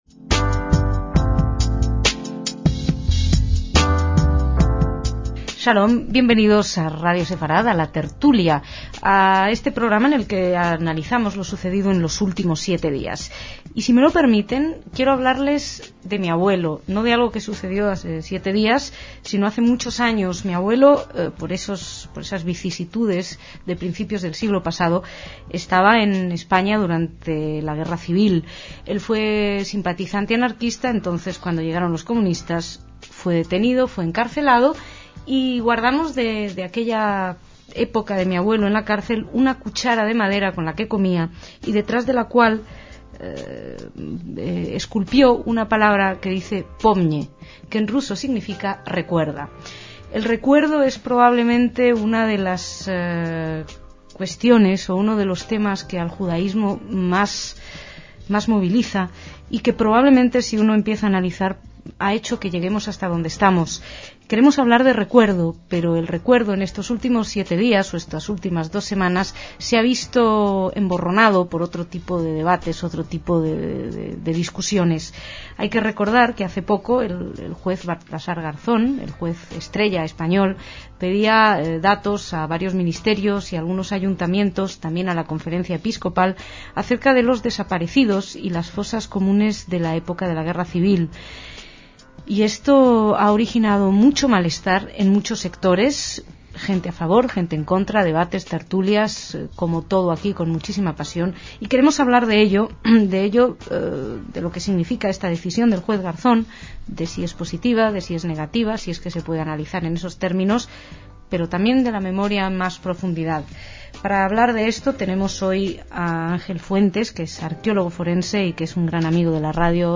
dos expertos en arqueología y sociología respectivamente, debatieron sobre la memoria histórica.